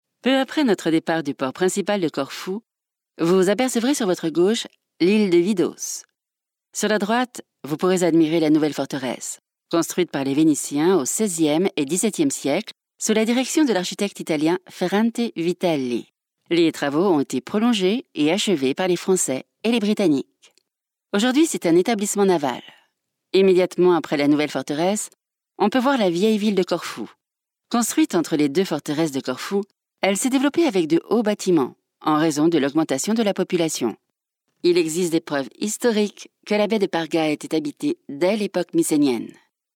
Guias de áudio
Minha voz é calorosa, feminina e adequada para narração, elearning, audiolivros, audioguias, mas também alguns comerciais, jogos.
Micro Neumann 103
Uma cabine Isovox em uma cabine à prova de som